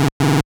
snd_error.wav